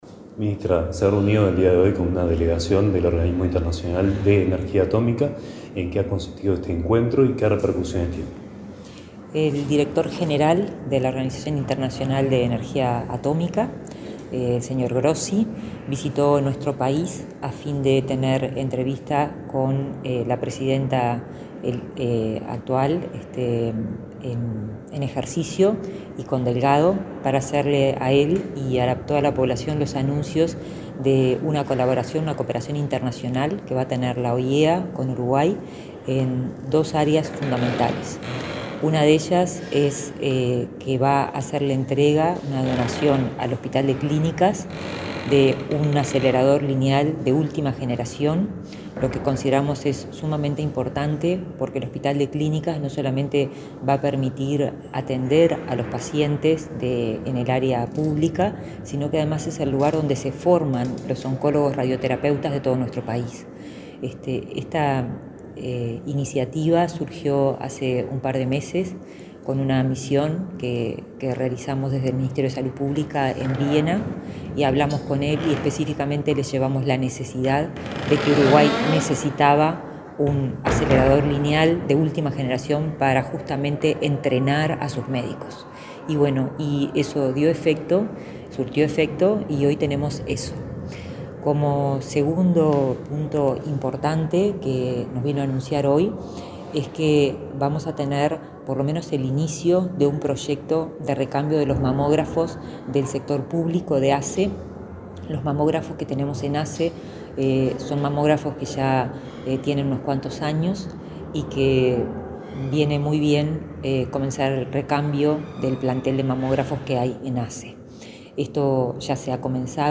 Entrevista a la ministra de Salud Pública, Karina Rando